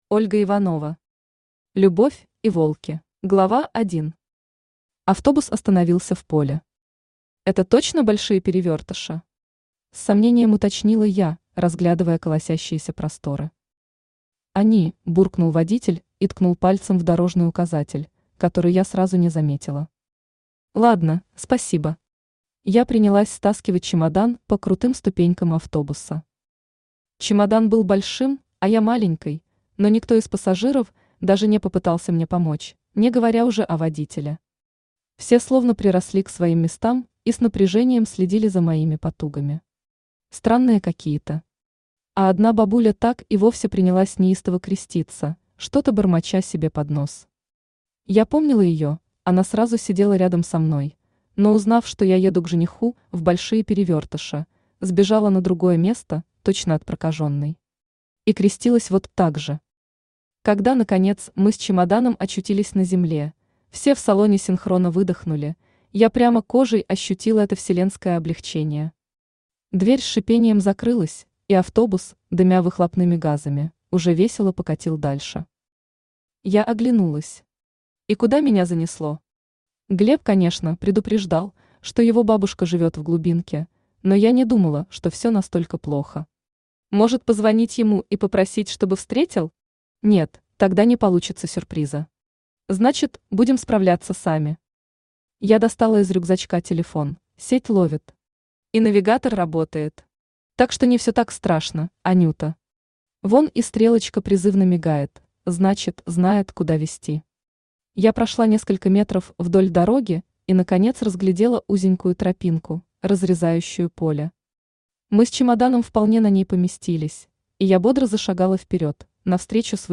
Аудиокнига Любовь и волки | Библиотека аудиокниг
Aудиокнига Любовь и волки Автор Ольга Дмитриевна Иванова Читает аудиокнигу Авточтец ЛитРес.